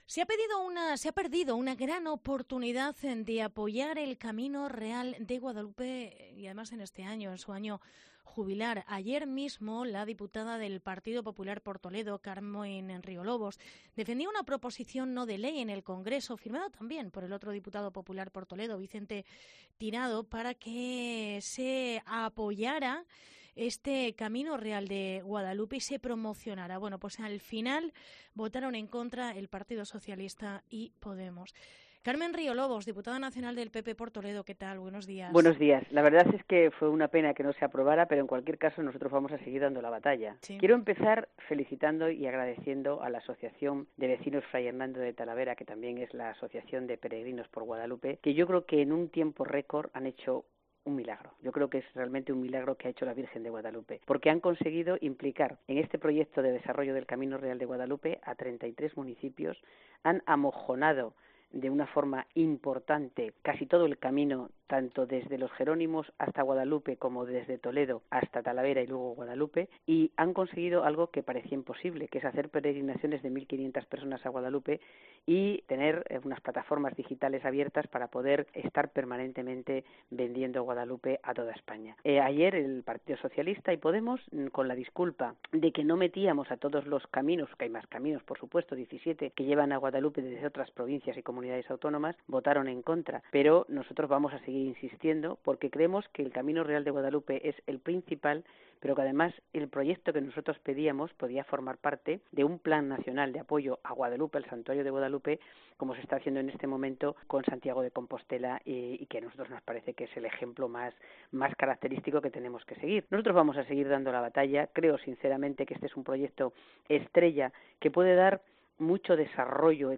Entrevista Carmen Riolobos